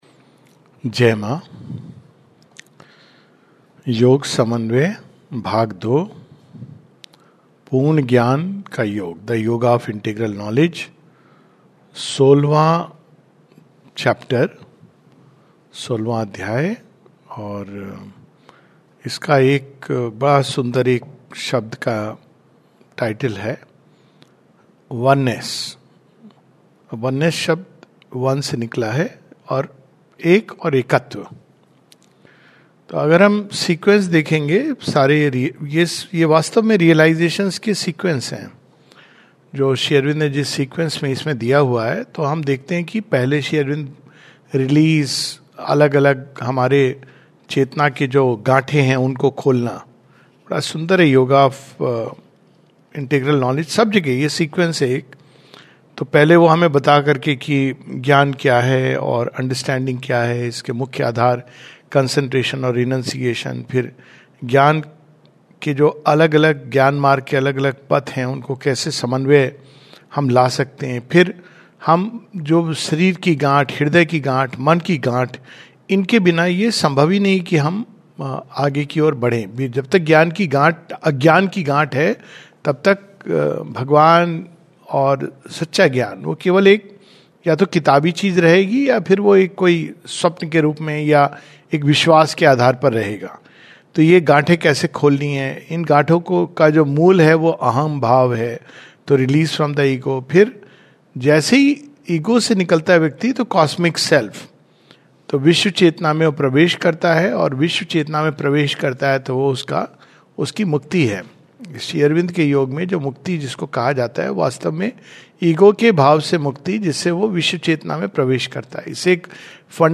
[Oneness] This is a summary of Chapter 16 of The Yoga of Integral Knowledge of the book The Synthesis of Yoga. A talk